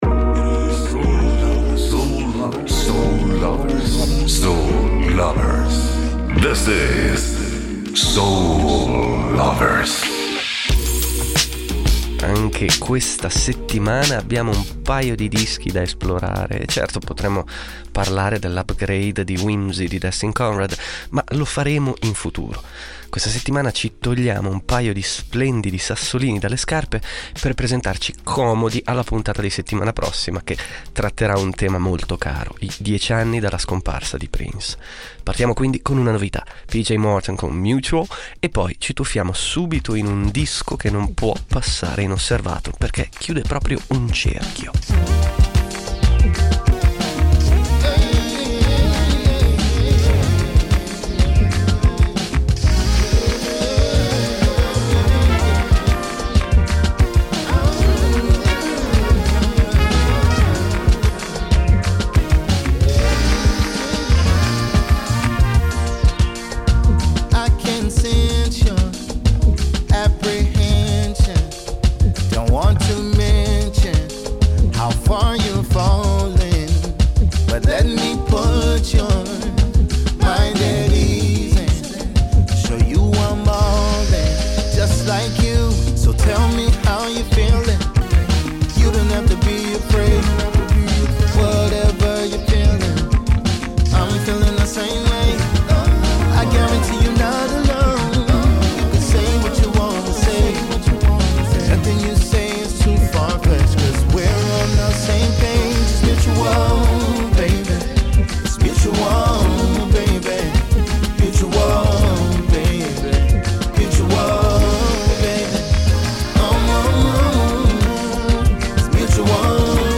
Rainbow Theater a Londra, 31 gennaio 1974.